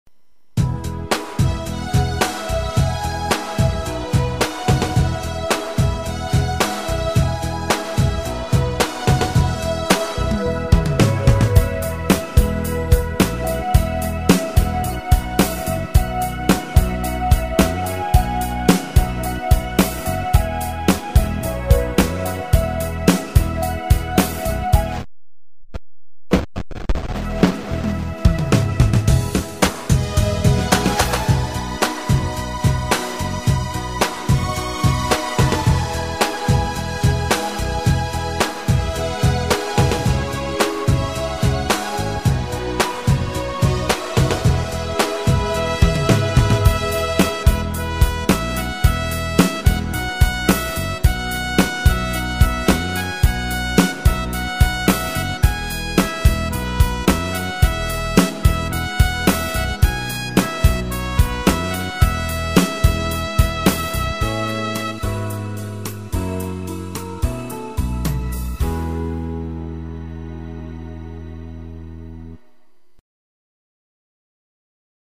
Nhạc không lời chủ đề bản thân